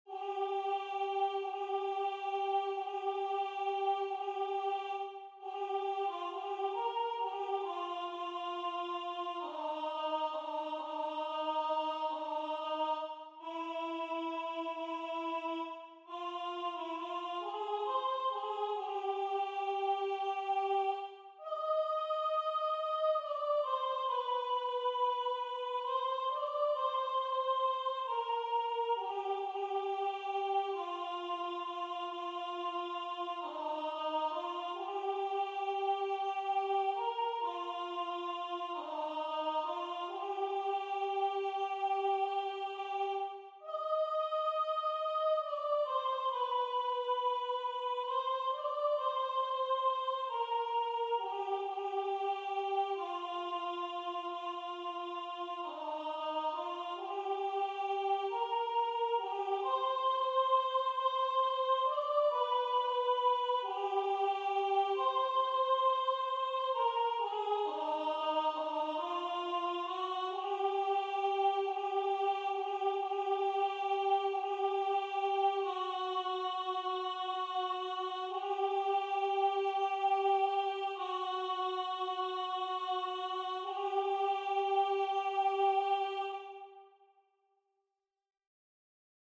Rendu voix synth.
Soprano